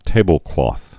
(tābəl-klôth, -klŏth)